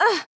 damage1.wav